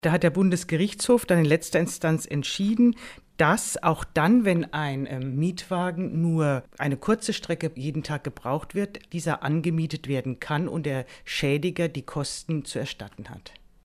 O-Töne / Radiobeiträge, Recht, , , ,